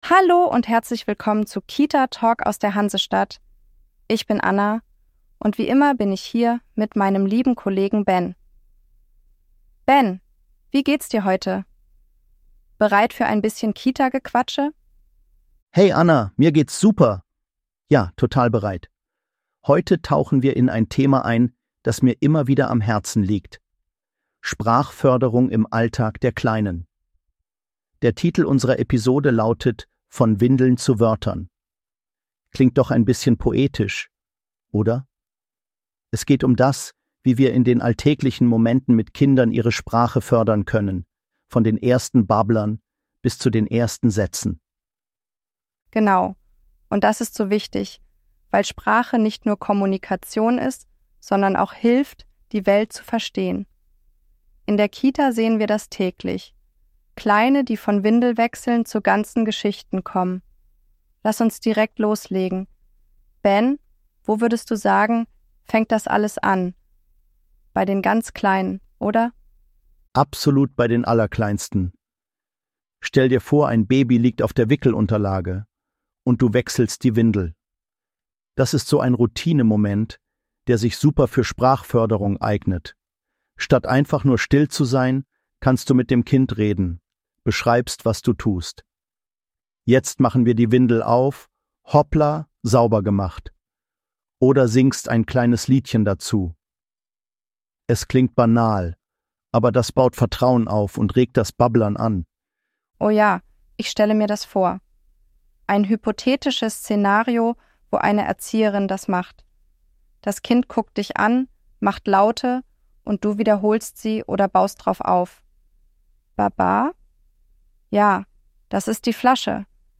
Inspiriert von innovativen Konzepten wie denen der KITA Hamburg, die natürliche Sprachentwicklung fördern, zeigen unsere AI-Hosts, wie alltägliche Momente wie Wickeln, Essen und Spielen zu wertvollen Sprachlernzeiten werden - von den ersten Babbellauten bis zu ganzen Sätzen.